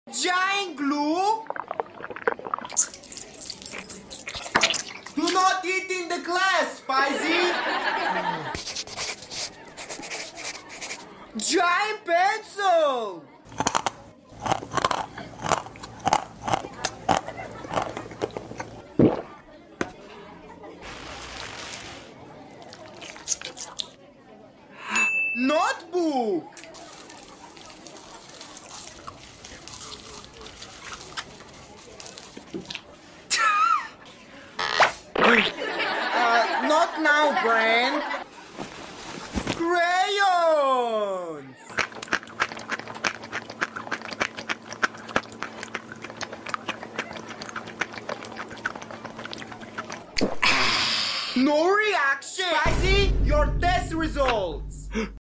Giant Edible School Supplies ASMR!? sound effects free download